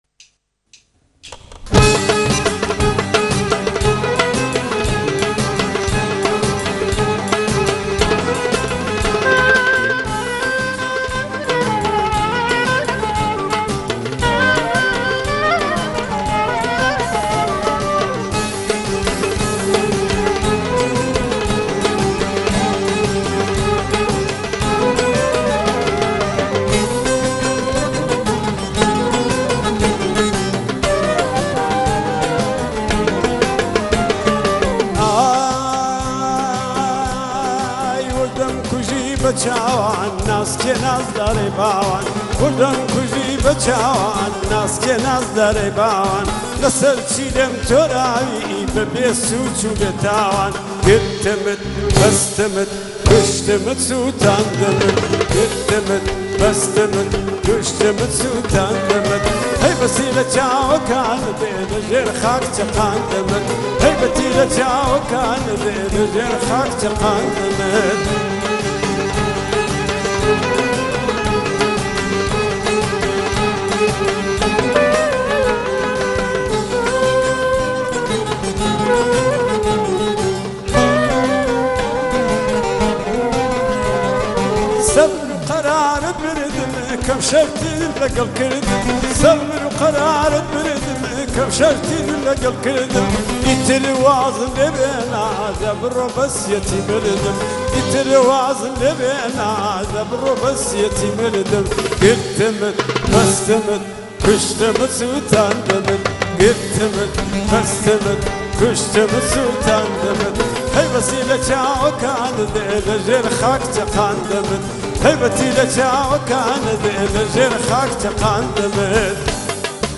آهنگ های کردی